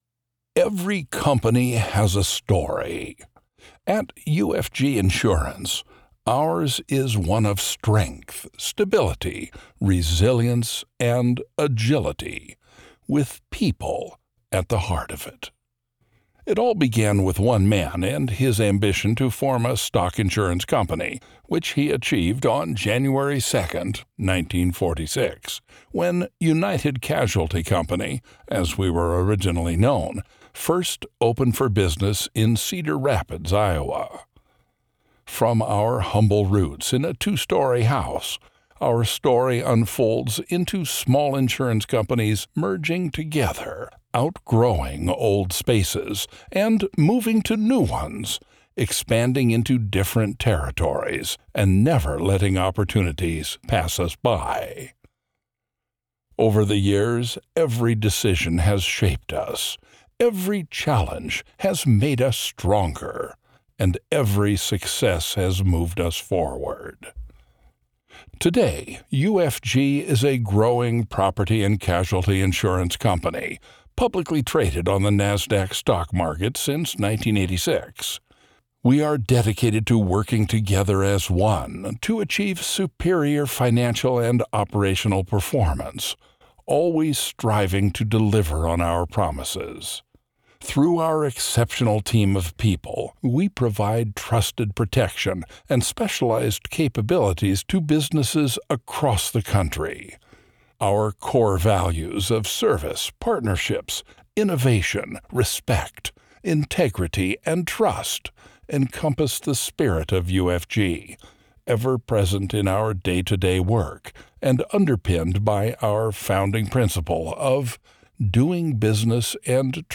Deep voice, dripping with gravitas and warmth.
Corp presentation for Insurance Company
English - Western U.S. English
Senior